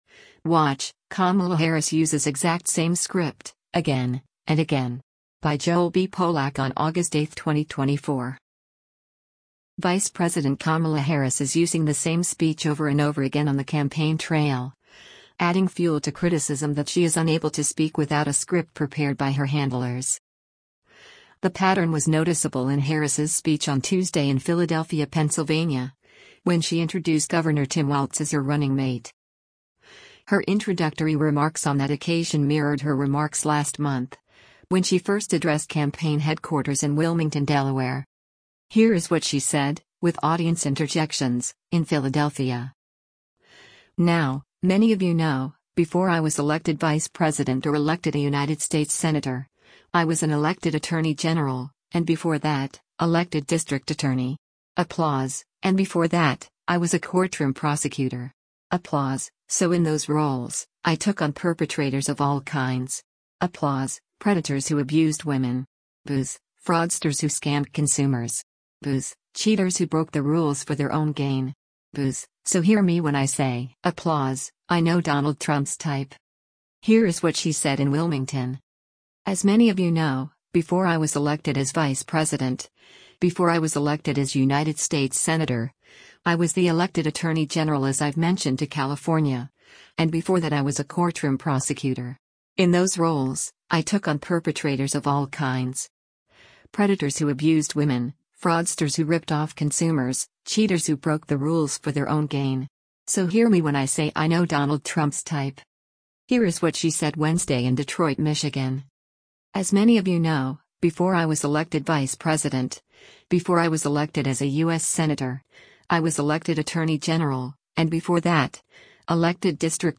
The pattern was noticeable in Harris’s speech on Tuesday in Philadelphia, Pennsylvania, when she introduced Gov. Tim Walz as her running mate.
Here is what she said (with audience interjections) in Philadelphia: